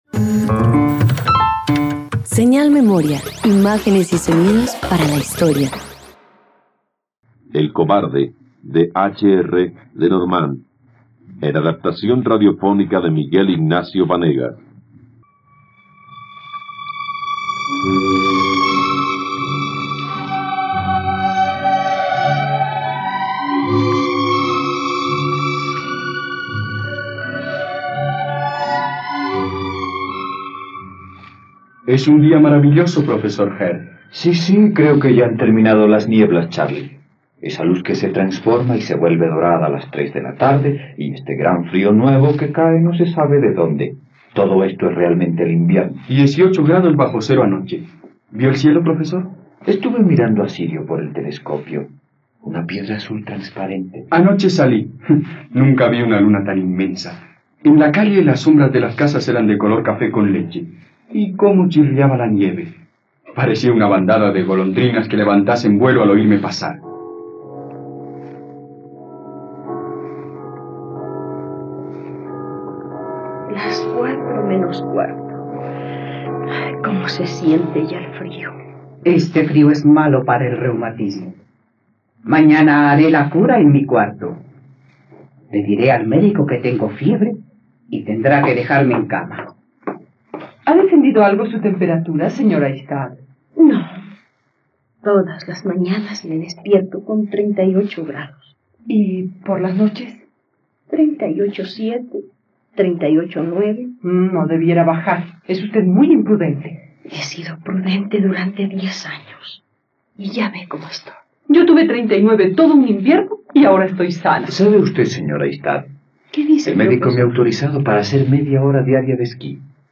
..Radioteatro. Escucha la adaptación de la obra "El cobarde" del dramaturgo francés Henri-René Lenormand, disponible en la plataforma de streaming RTVCPlay.